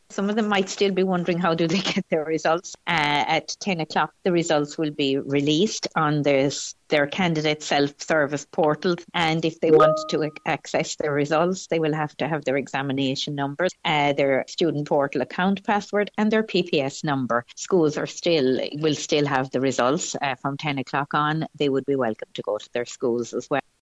Guidance Counsellor